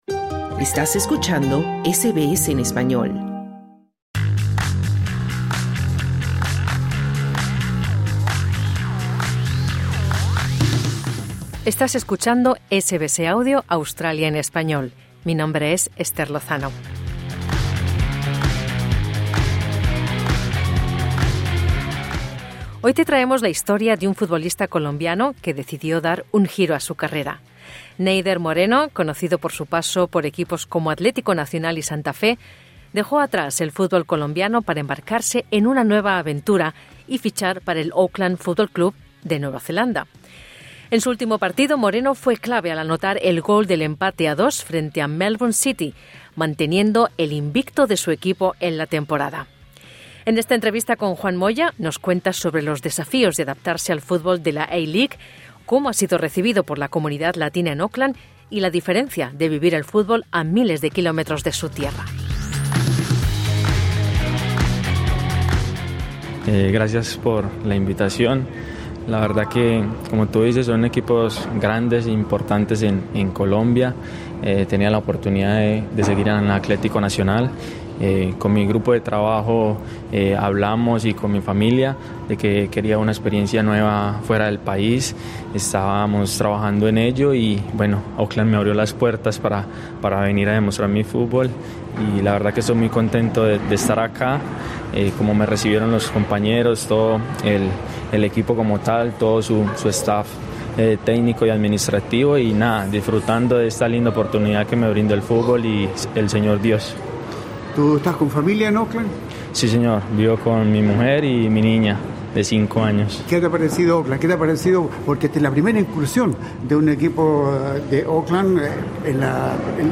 En su último partido, Moreno fue clave al anotar el gol del empate 2-2 frente a Melbourne City, manteniendo el invicto de su equipo en la temporada. El atacante colombiano comparte en entrevista para SBS Spanish los desafíos y diferencias que ha encontrado al adaptarse al fútbol australiano.